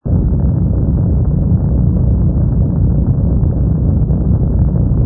rumble_freighter.wav